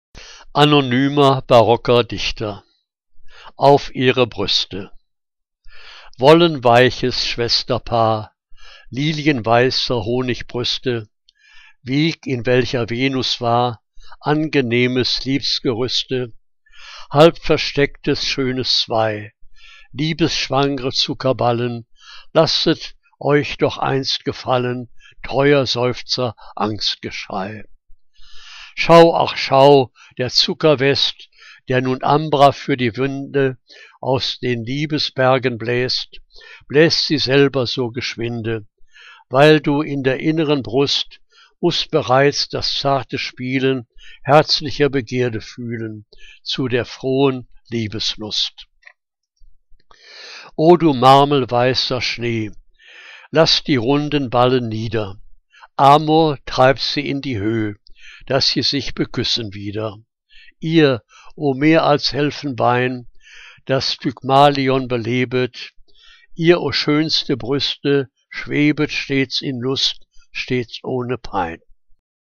Liebeslyrik deutscher Dichter und Dichterinnen - gesprochen (Anonyme Barockdichter)